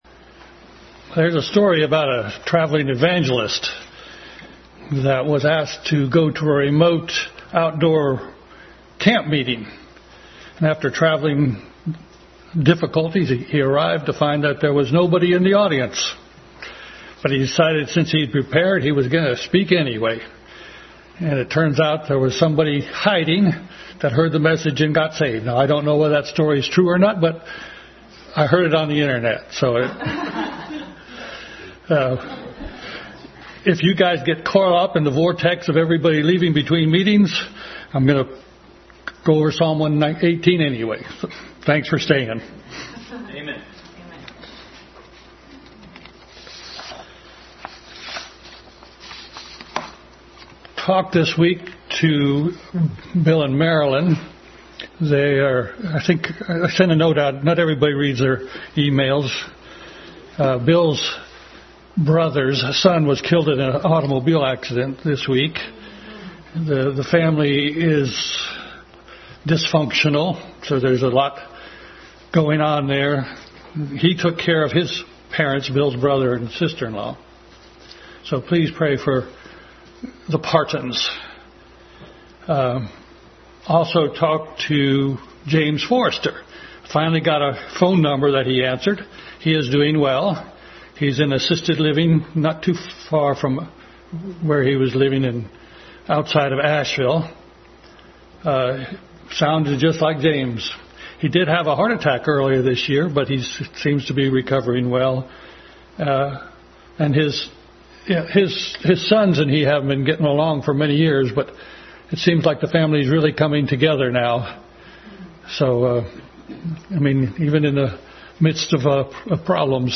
Passage: Psalm 118:1-14, Ezra 3:11, Psalm 117 Service Type: Family Bible Hour